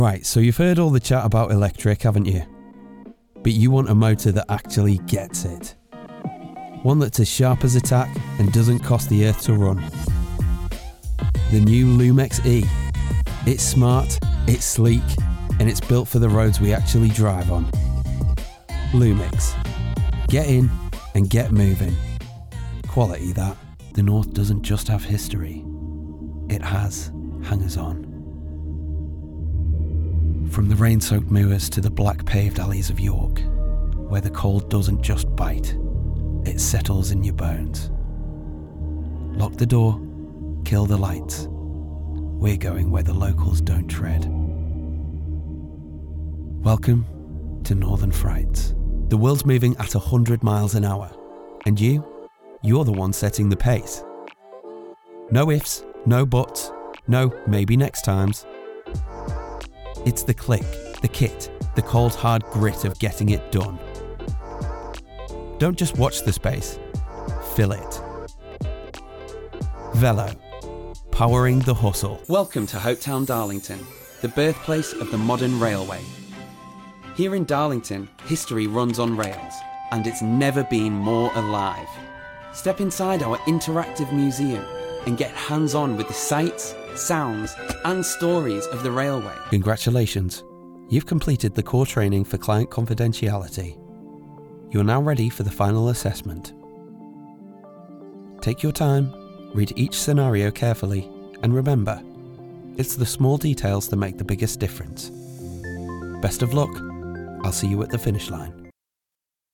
Range 20s - 40s
Natural North East tones with a professional edge.